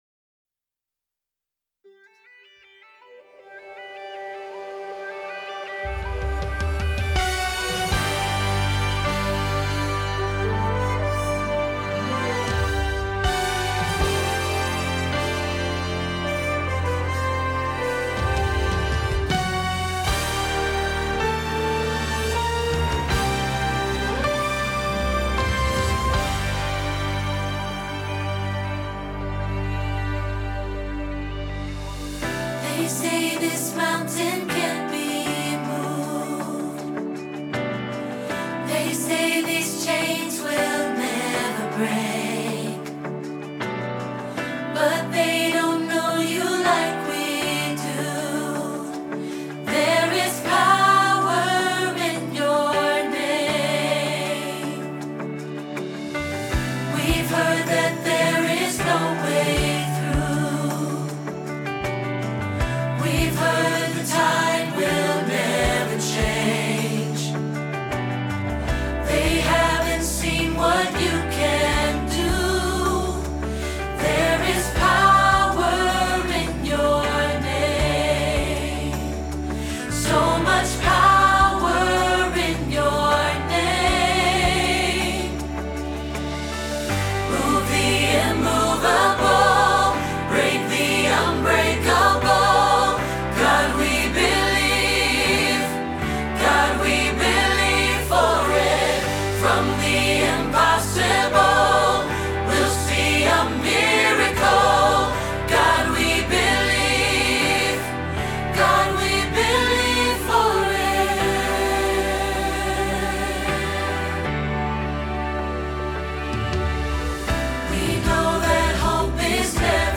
Believe for It – Alto – Hilltop Choir